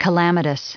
Prononciation du mot calamitous en anglais (fichier audio)
Prononciation du mot : calamitous